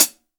Hat cl tight.WAV